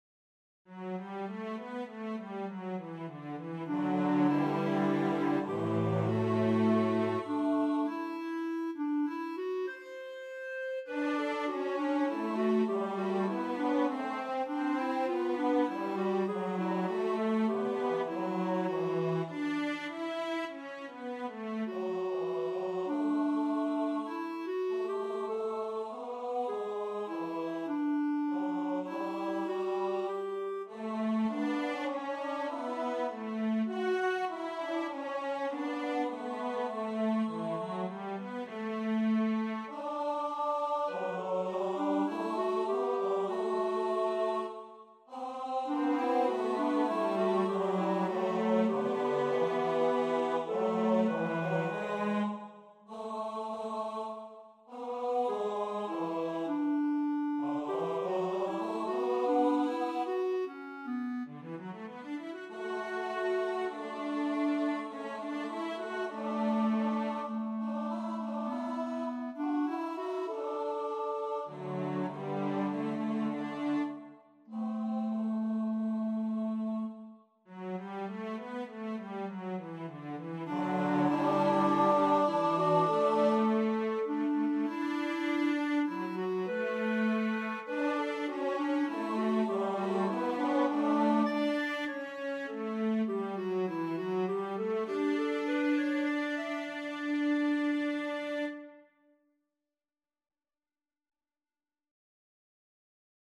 for Voices and Instruments
Tenor; Clarinet; Cello